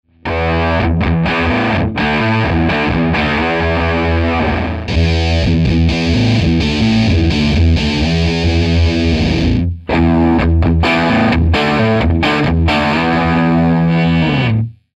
BEHRINGER/SF300はクラシックファズ、グランジ、そしてゲインブーストの三種類のエフェクトモードを搭載。ヴィンテージテイスト溢れるファズサウンドです。